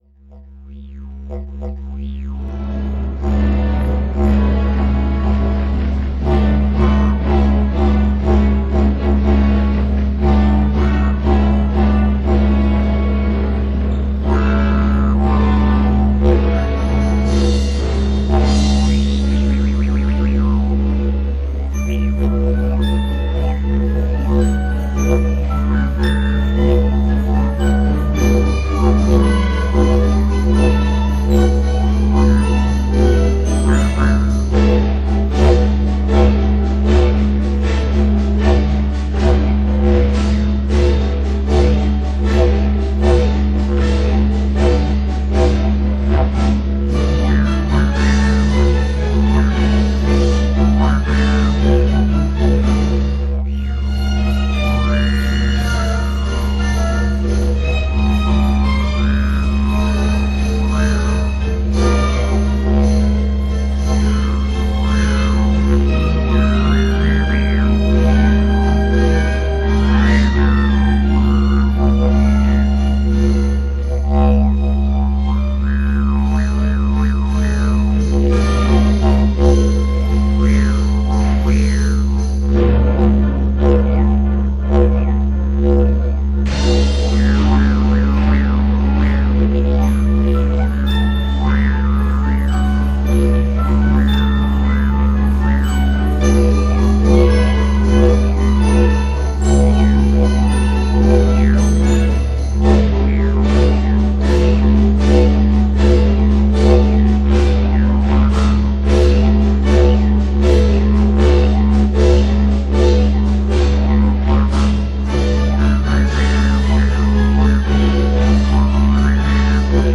Didgeridoo playing on the background of the Croatian opera